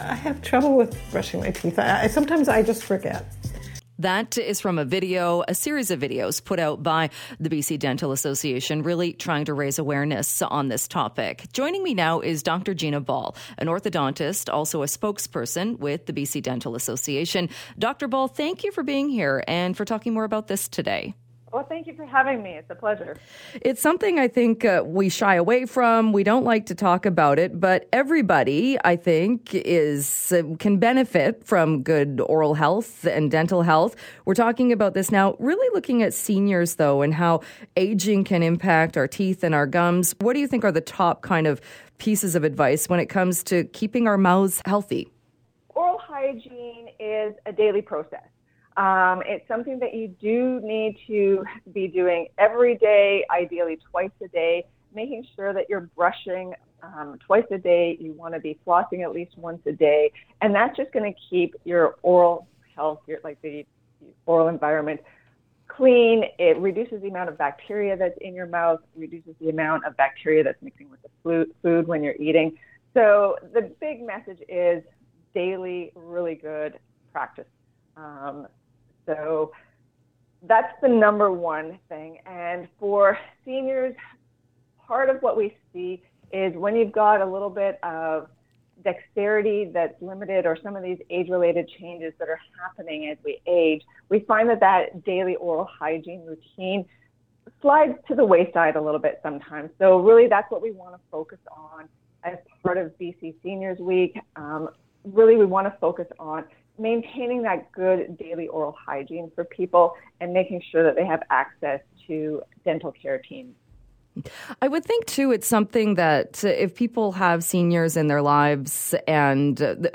CKNW Radio Interview